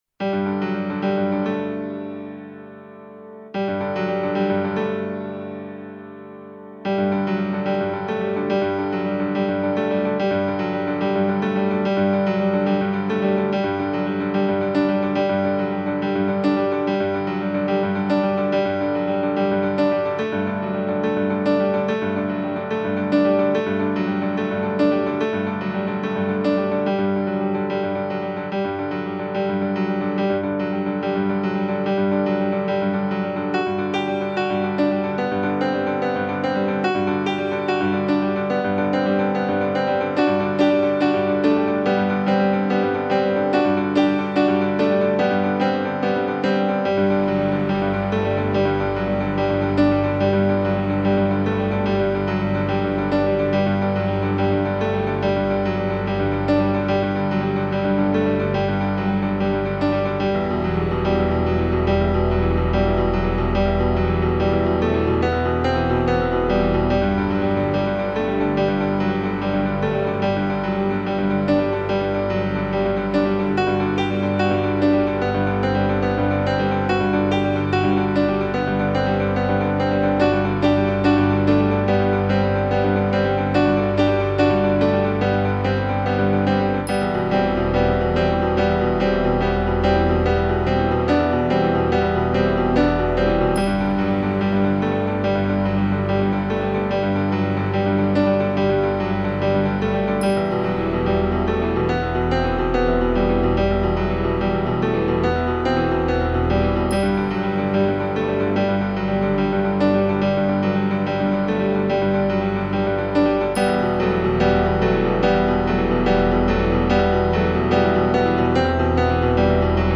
Piano and synthesizer.